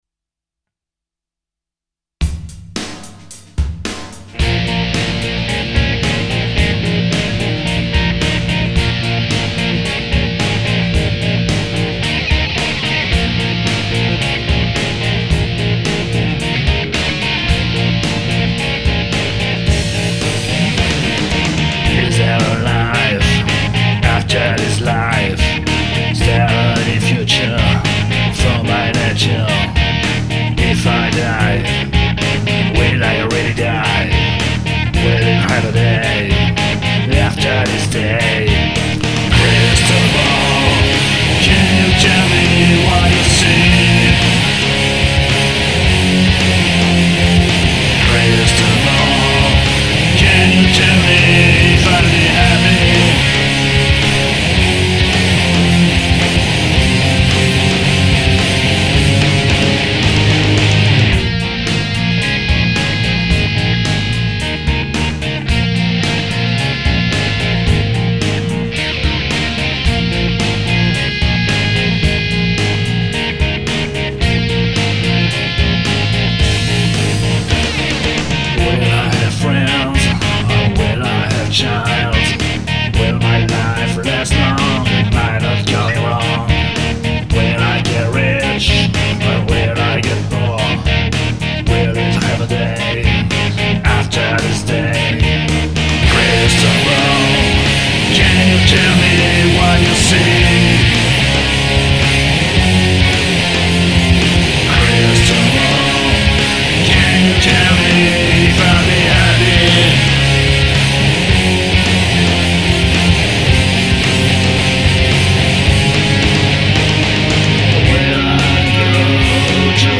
Matos : Essentiellement des Les Paul, l'Explorer, la PRS 91